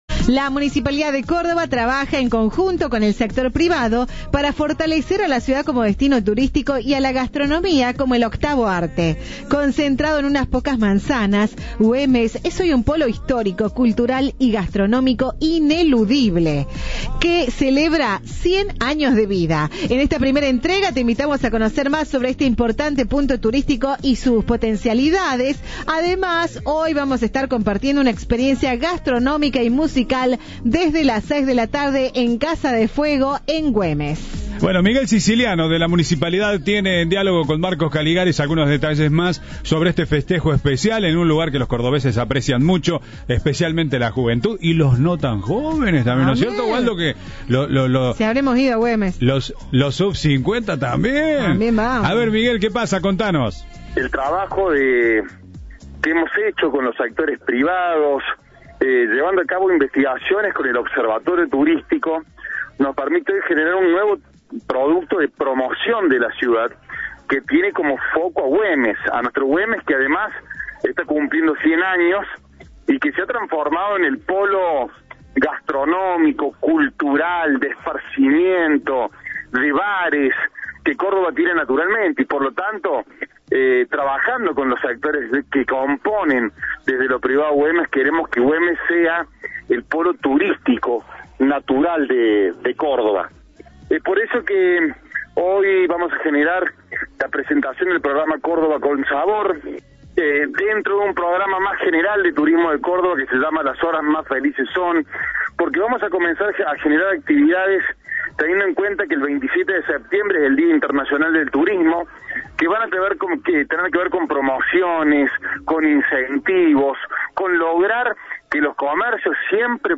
Entrevista de Viva La Radio